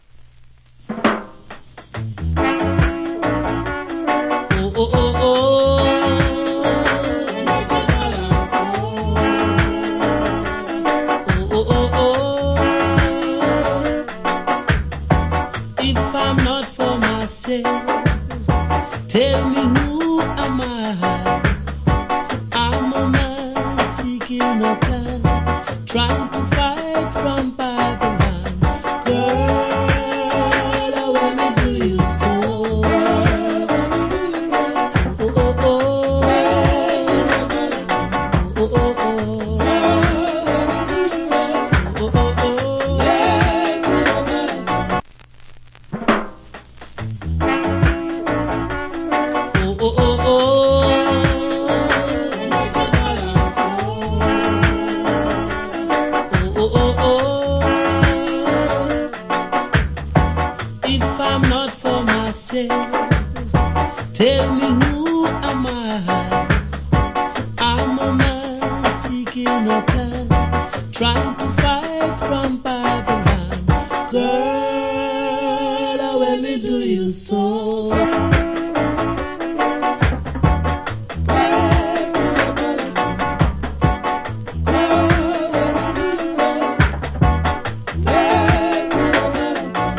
Wicked US Roots Rock Vocal.